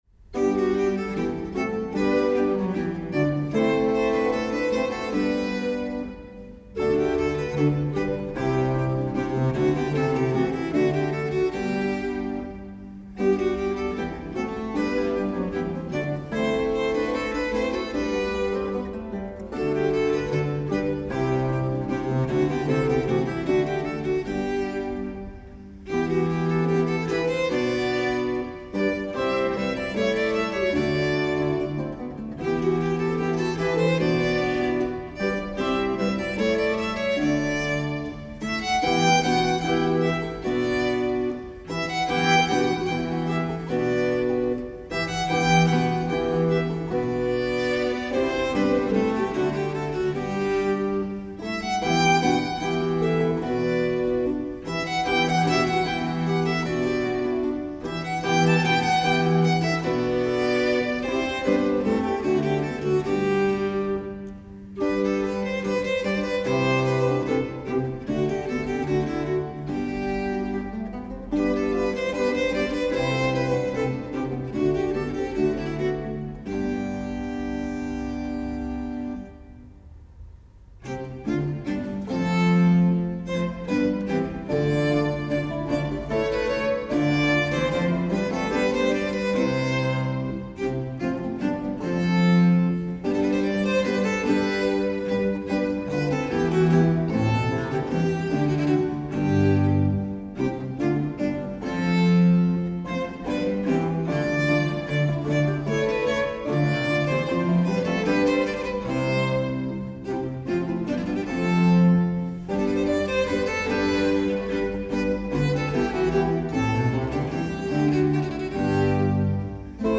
Orquesta
Danza